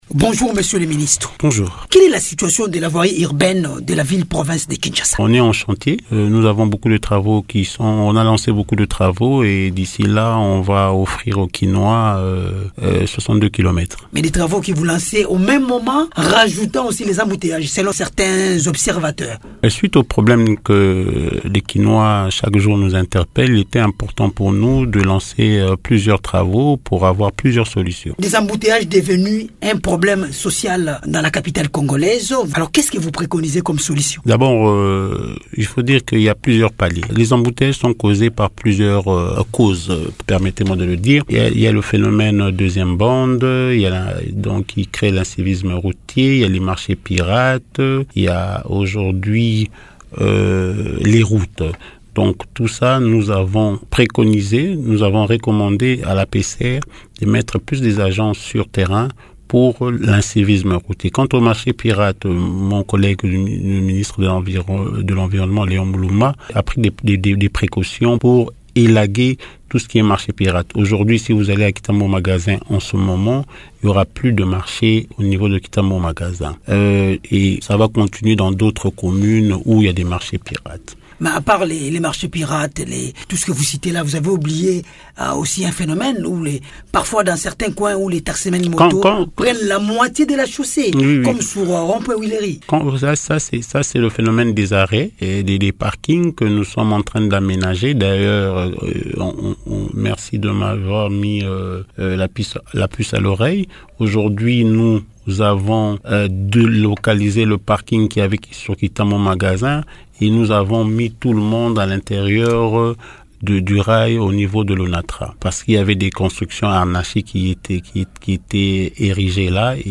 Invité de Radio Okapi, le ministre provincial des Transports et Voies de communication de Kinshasa, Bob Amisso assure doter, pour bientôt, la capitale de 62 km de route asphaltée.